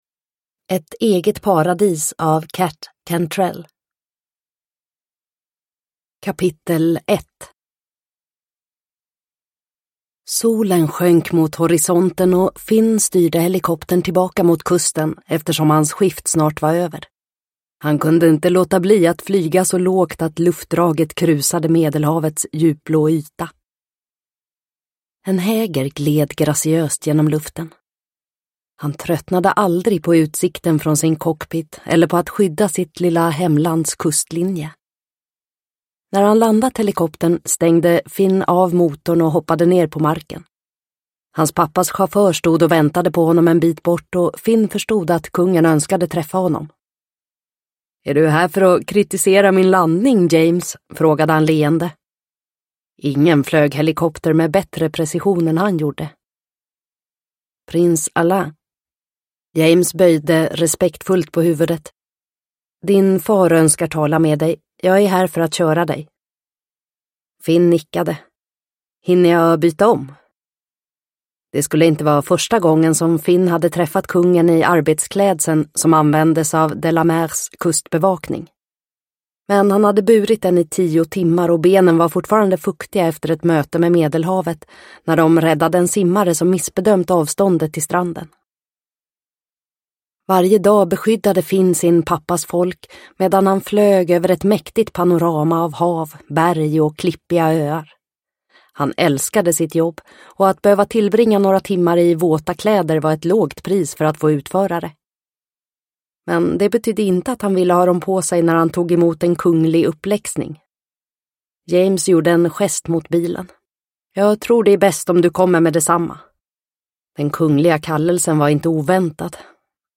Ett eget paradis – Ljudbok – Laddas ner